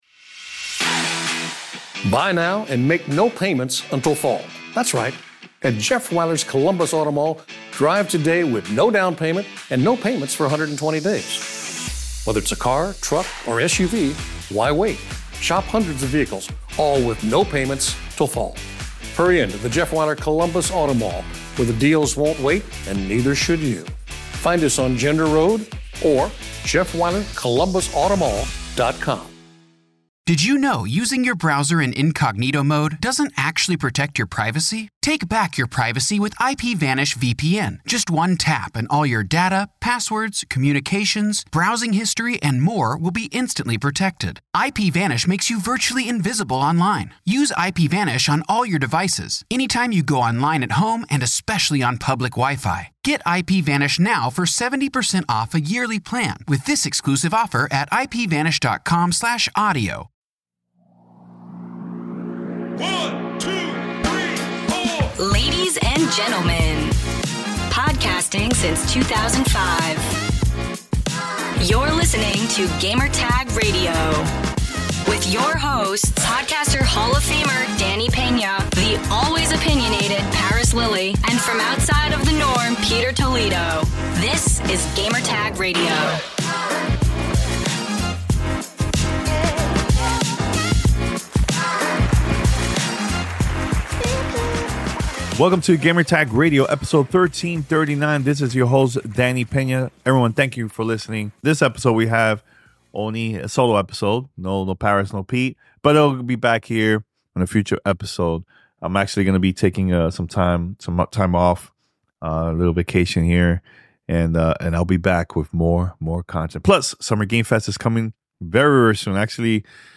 Tales of Kenzera: Zau Interview with Abubakar Salim
This week on Gamertag Radio, interview with the creative director at Surgent Studios, Abubakar Salim about Tales of Kenzera: Zau.